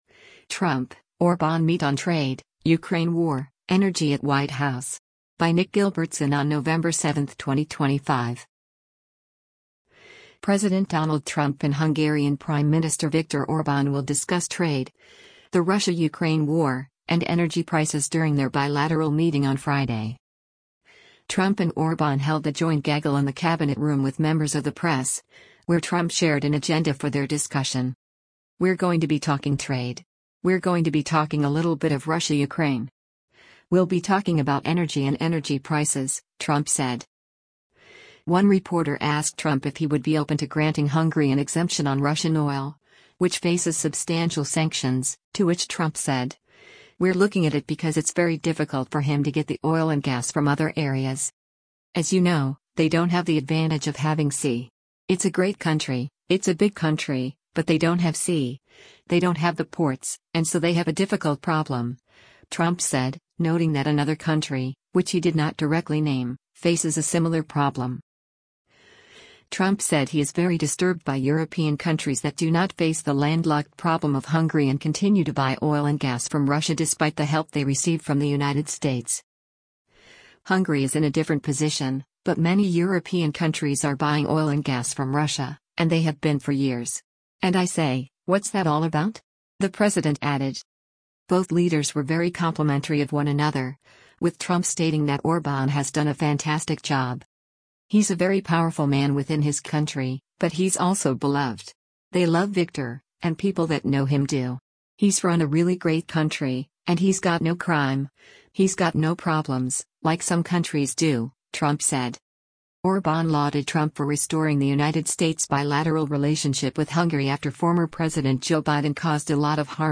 Trump and Orbán held a joint gaggle in the Cabinet Room with members of the press, where Trump shared an agenda for their discussion.
One reporter asked Trump if he would be open to granting Hungary an exemption on Russian oil, which faces substantial sanctions, to which Trump said, “We’re looking at it because it’s very difficult for him to get the oil and gas from other areas.”